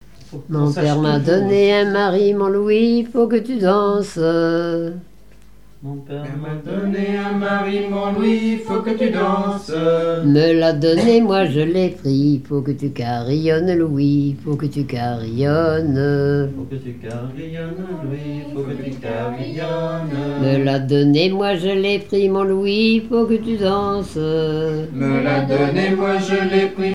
ronde à la mode de l'Epine
Pièce musicale inédite